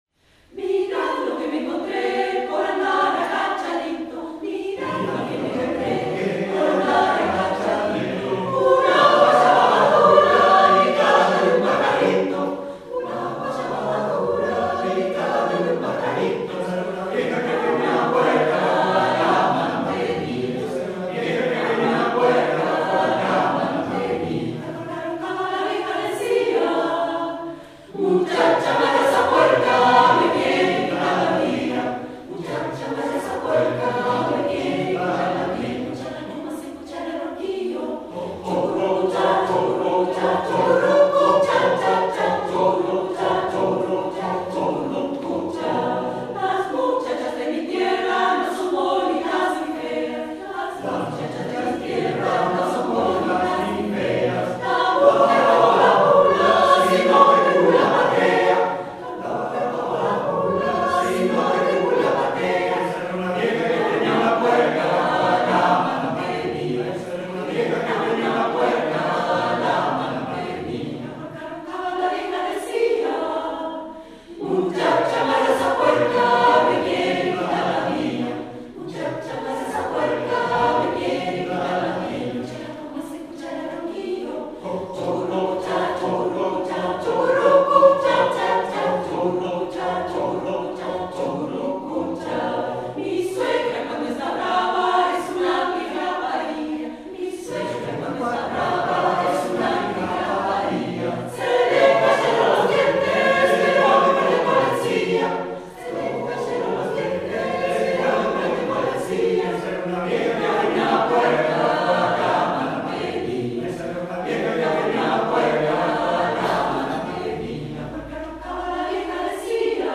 Tradicional Venezolana.